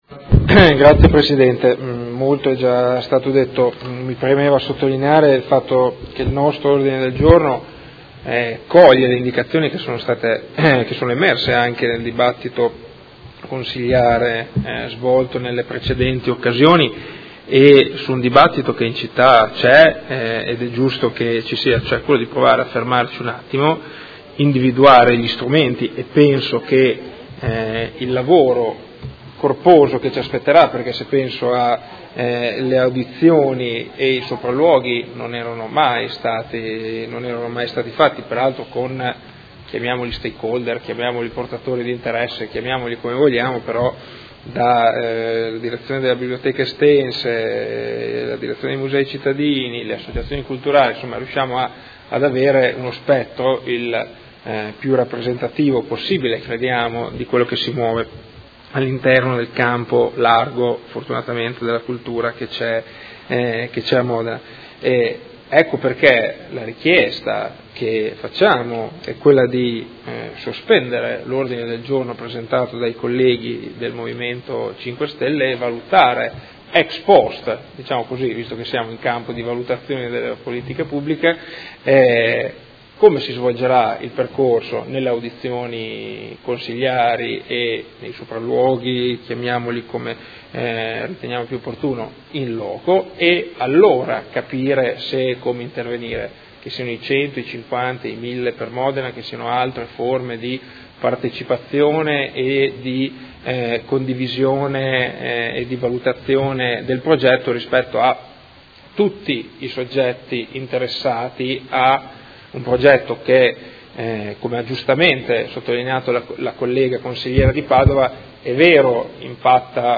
Seduta del 12/05/2016.